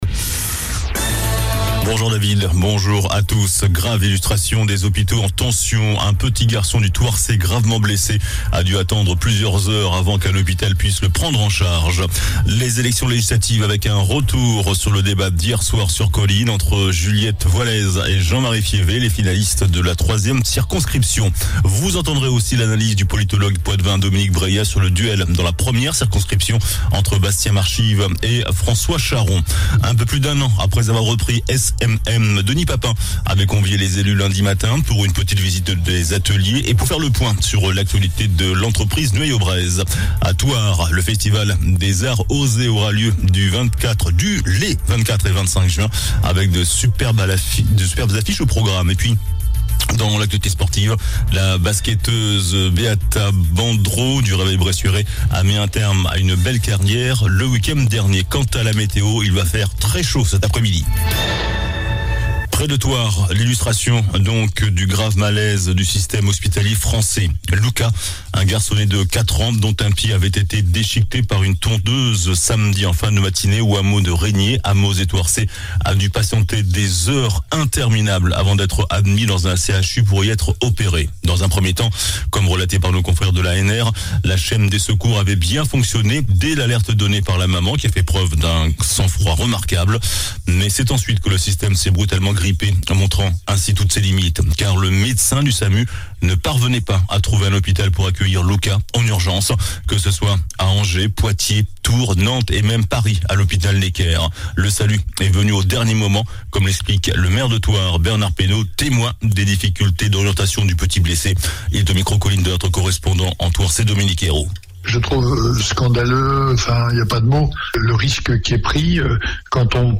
JOURNAL DU MERCREDI 15 JUIN ( MIDI )